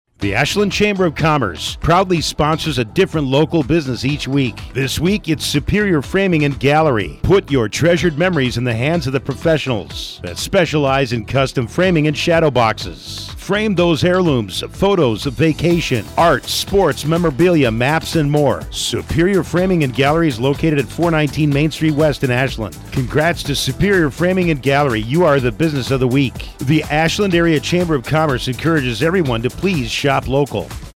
Each week the Ashland Chamber of Commerce highlights a business on Heartland Communications radio station WATW 1400am. The Chamber draws a name from our membership and the radio station writes a 30-second ad exclusively for that business.